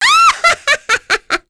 Miruru-Vox_Happy4.wav